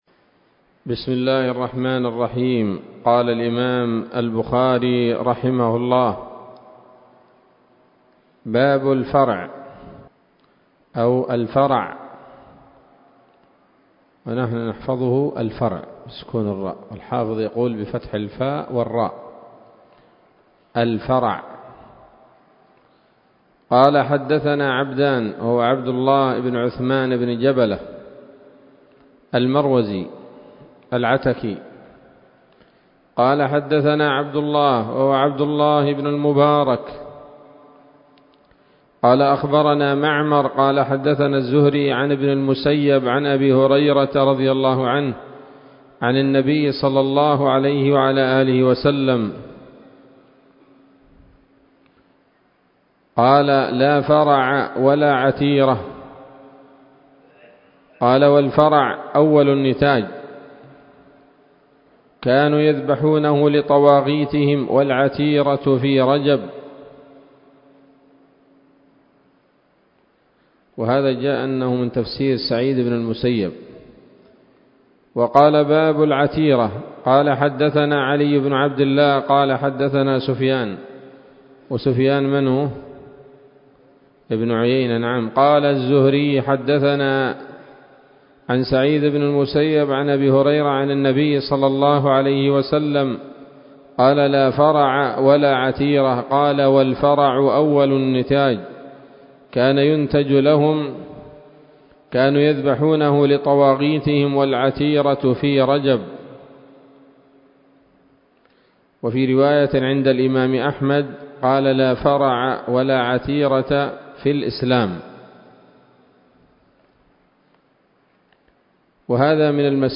الدرس الثالث وهو الأخير من كتاب العقيقة من صحيح الإمام البخاري